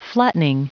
Prononciation du mot flattening en anglais (fichier audio)
Prononciation du mot : flattening